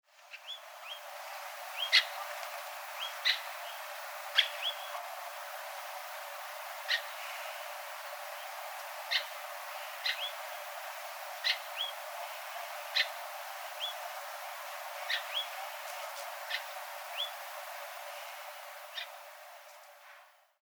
ハギマシコ
【分類】 スズメ目 アトリ科 ハギマシコ属 ハギマシコ 【分布】北海道(留鳥、冬鳥)、本州(冬鳥)、九州(希な冬鳥) 【生息環境】高標高の岩礫地、崖地に生息 冬期は海岸の崖地、疎林、草原、荒地に飛来 【全長】16cm 【主な食べ物】種子 【鳴き声】地鳴き 地鳴き2 【聞きなし】「ヂュッ」「ヂュー」「チュー」